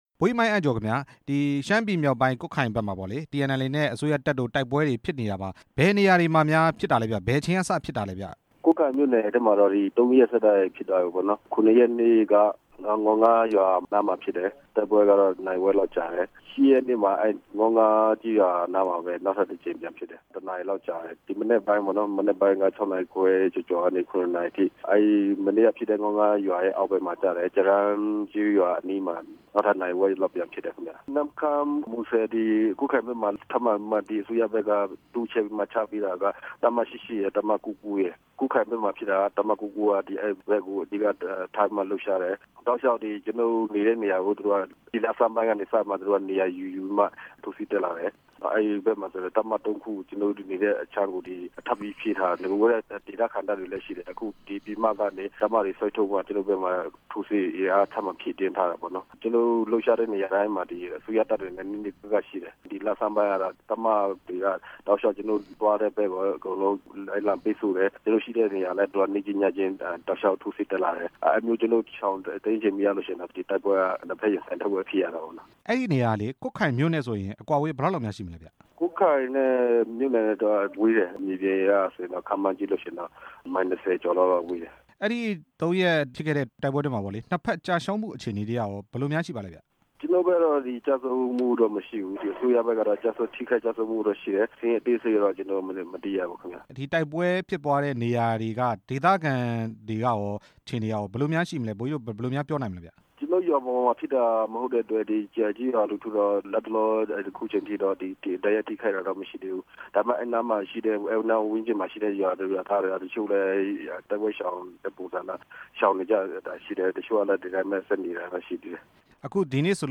အစိုးရတပ်မတော်နဲ့ TNLA တို့ တိုက်ပွဲတွေ ပြင်းထန်တဲ့အကြောင်း မေးမြန်းချက်